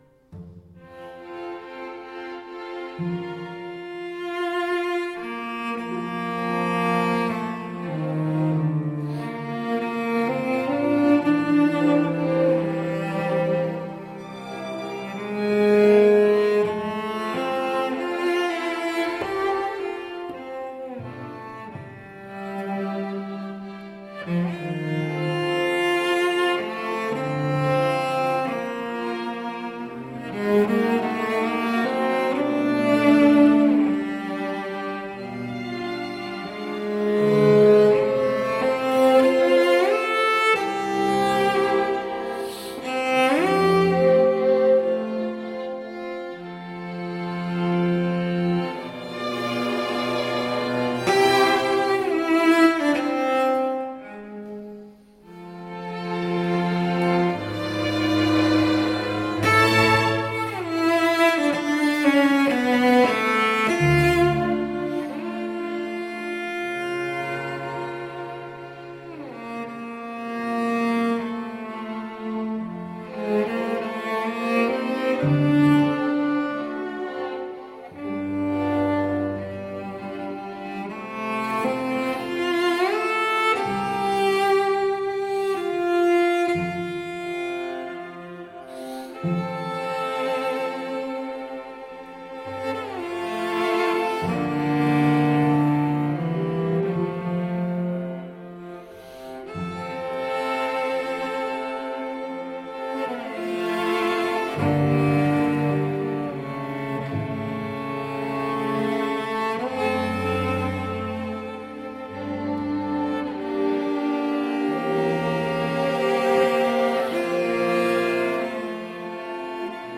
Concert piece for violoncello with quartet accompaniment
Classical, Romantic Era, Instrumental, Orchestral, Cello